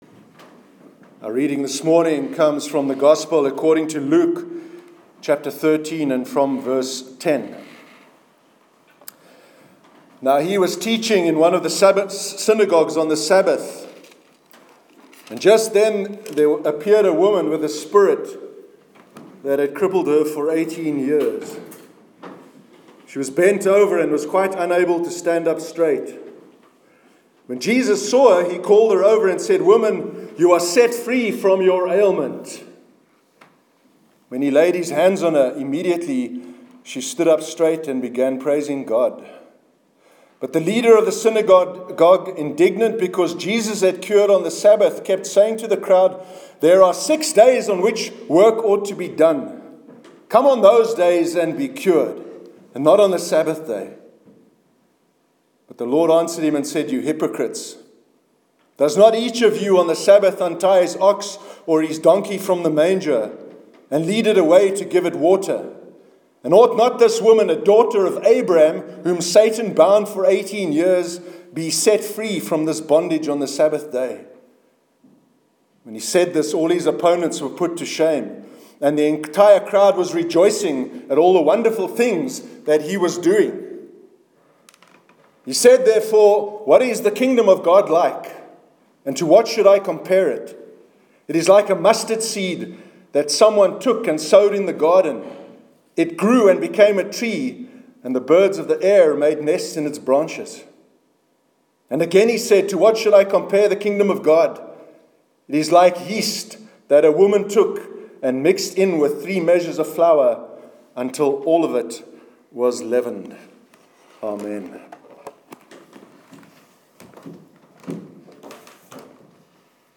Sermon on Jesus’ Treatment of Women- 21st May 2017